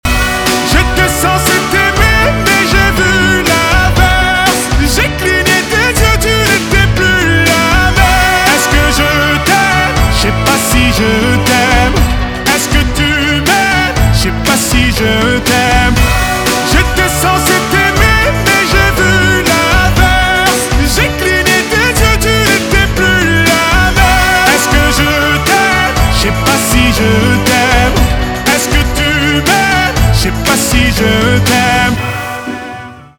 поп
соул
битовые
чувственные
грустные